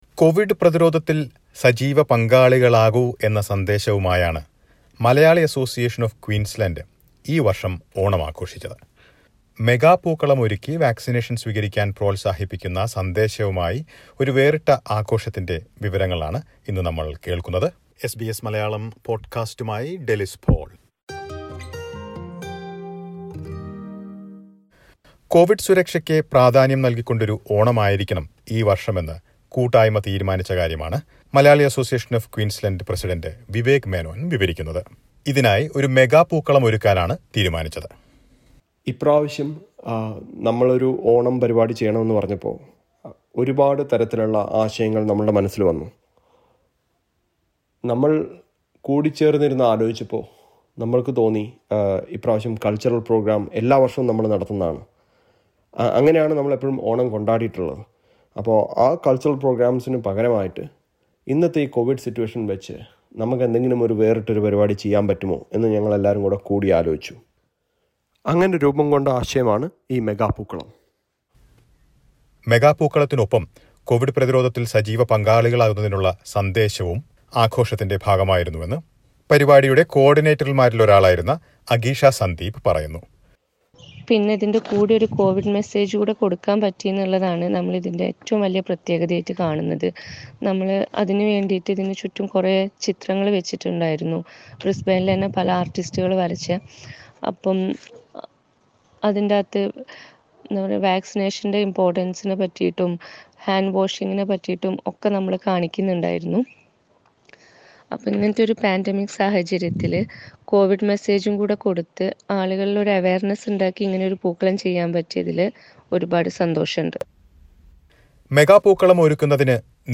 Malayalee Association of QLD celebrated Onam with a special Covid message. Listen to a report.